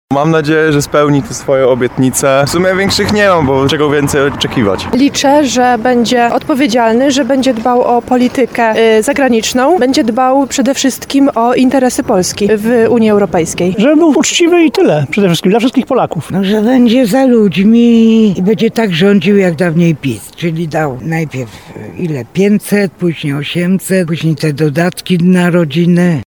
To wydarzenie budzi duże emocje w całym kraju – także w Tarnowie, gdzie zapytaliśmy mieszkańców, czego oczekują od nowej głowy państwa.
Tarnowianie podzielili się z nami swoimi nadziejami i opiniami.
– Mam nadzieję, że spełni te swoje obietnice. W sumie większych nie mam, bo czego więcej oczekiwać – podkreśla młody student.
30sonda_prezydent.mp3